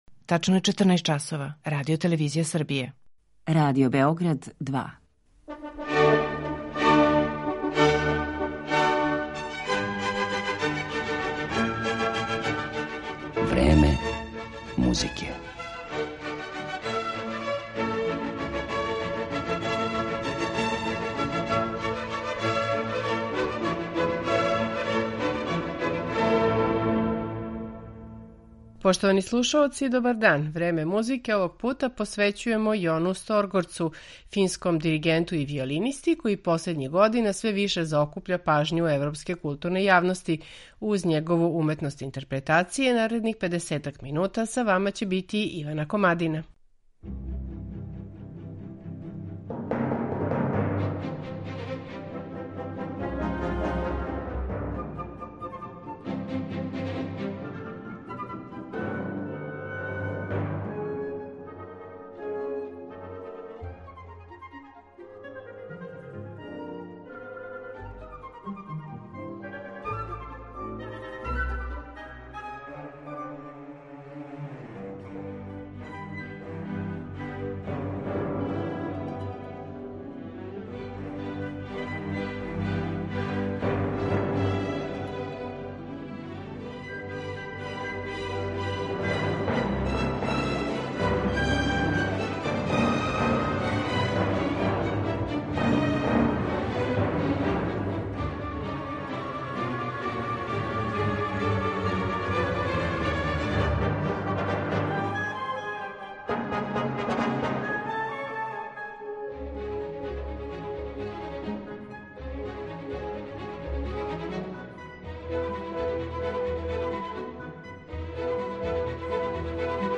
оркестарских дела